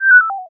question_001.ogg